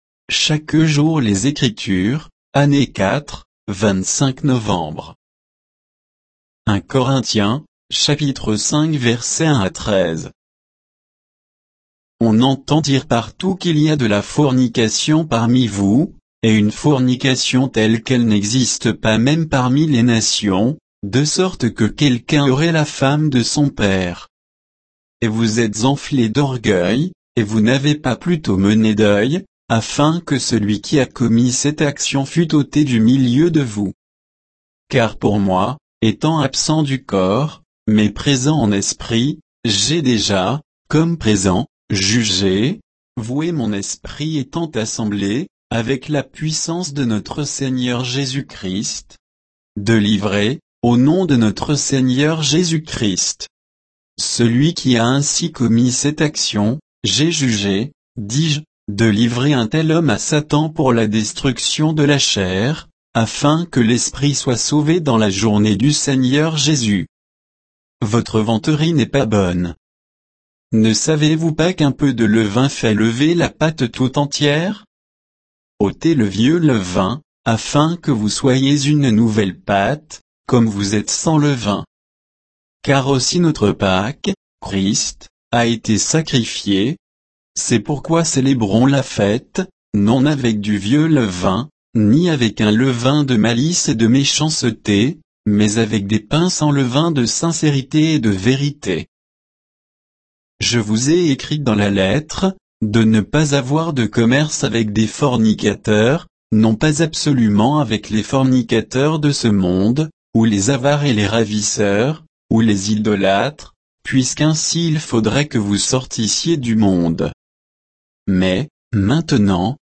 Méditation quoditienne de Chaque jour les Écritures sur 1 Corinthiens 5, 1 à 13